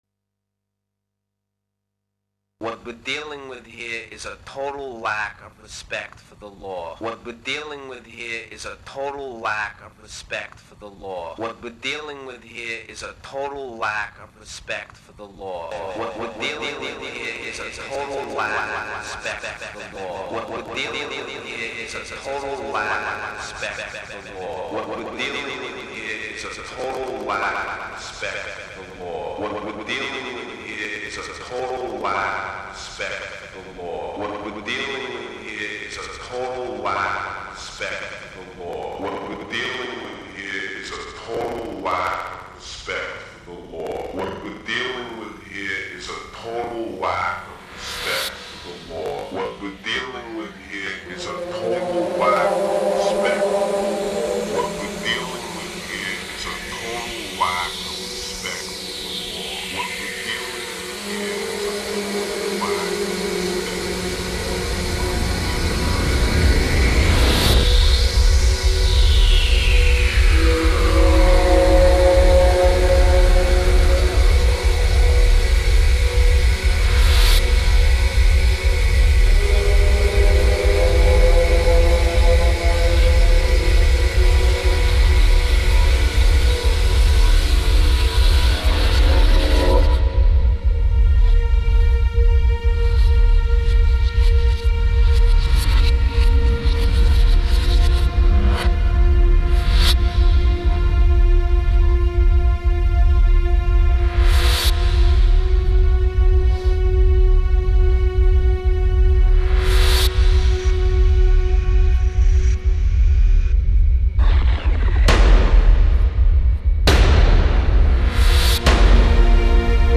celebration" music